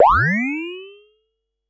Index of /musicradar/essential-drumkit-samples/Vermona DRM1 Kit
Vermona Fx 02.wav